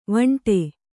♪ vaṇṭe